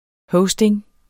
Udtale [ ˈhɔwsdeŋ ]